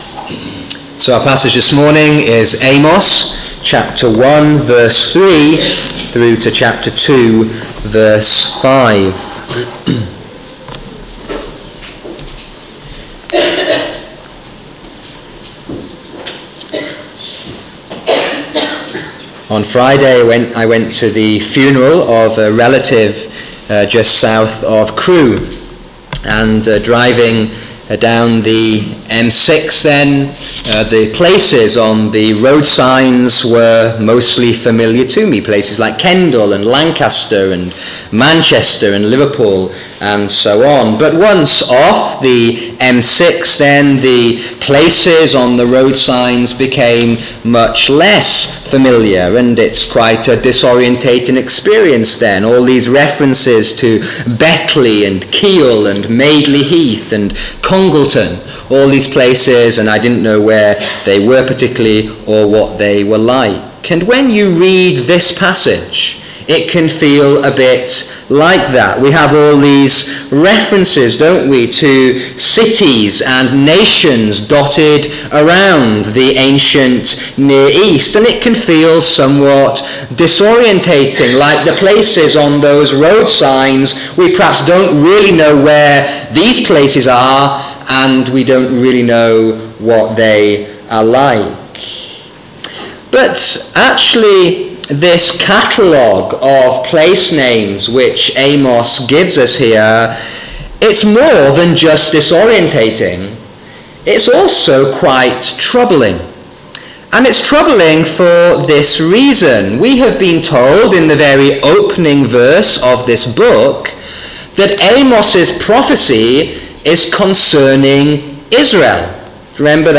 Sermons: reverse order of upload